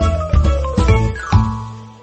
fail2.mp3